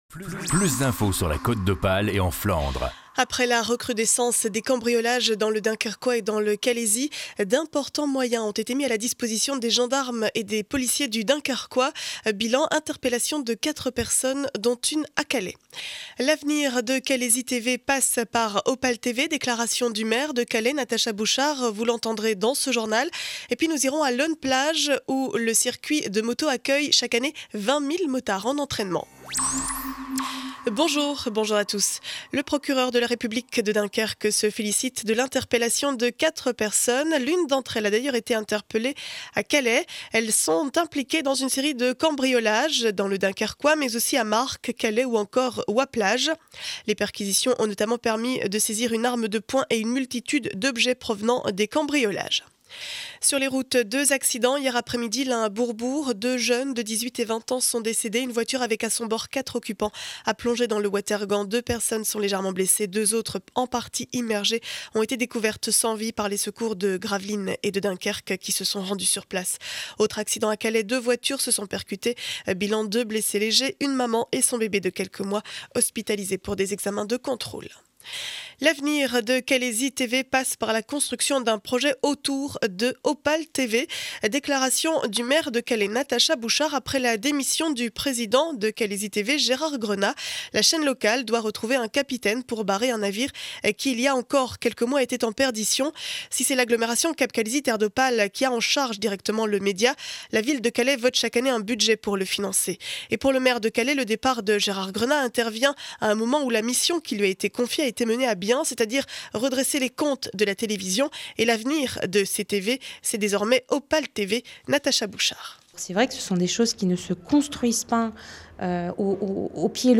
Journal du mardi 24 avril 2012 7 heures 30 édition du Dunkerquois.